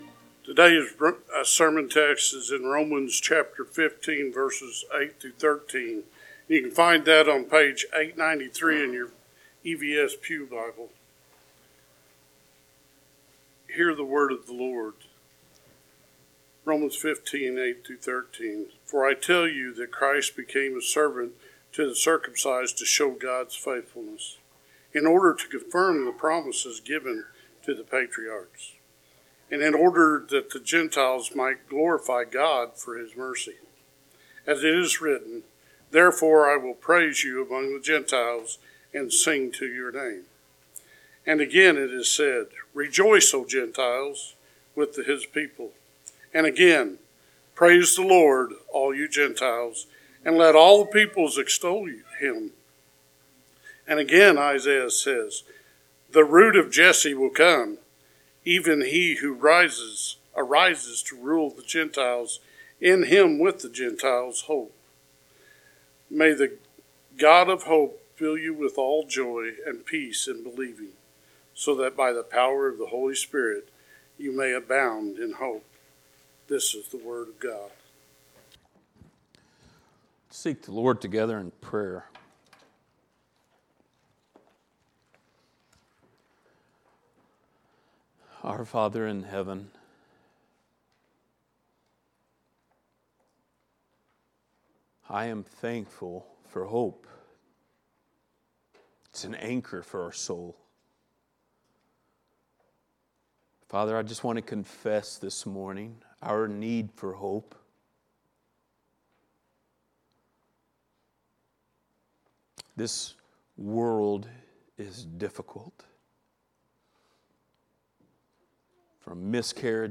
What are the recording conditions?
Series: Book of Romans Passage: Romans 15:8-13 Service Type: Sunday Morning